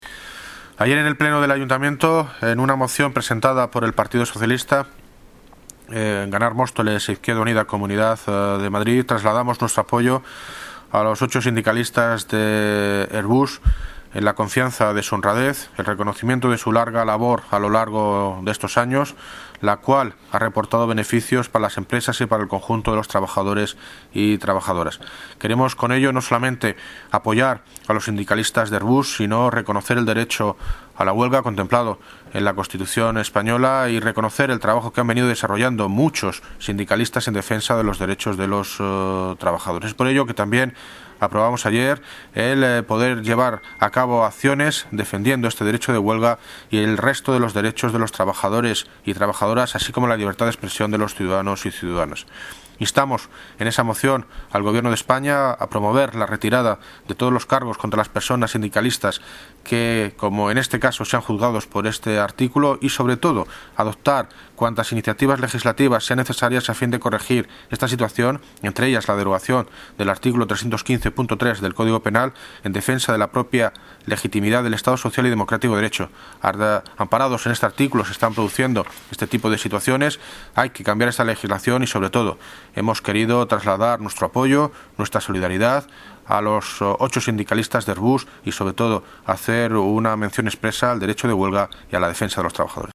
Audio - David Lucas (Alcalde de Móstoles) sobre Detenidos de Airbus